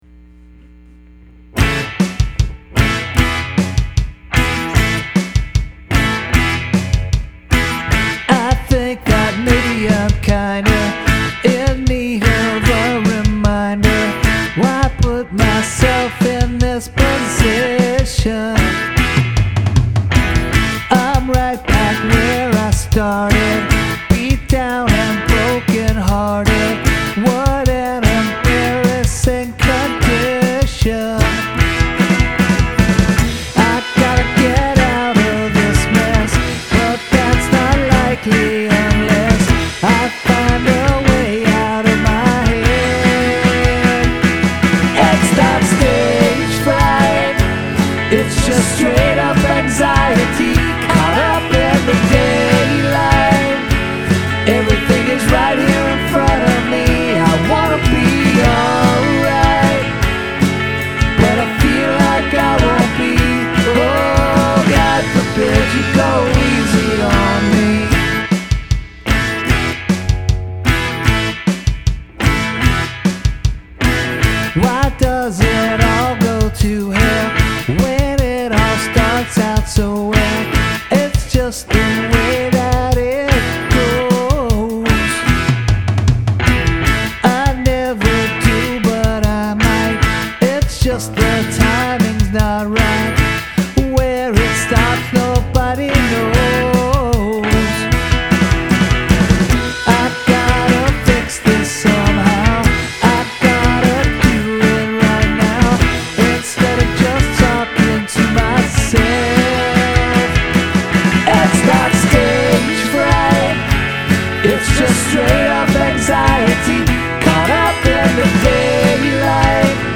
A bright tune and production.
Punky power pop masterpiece.
So catchy and hook-y!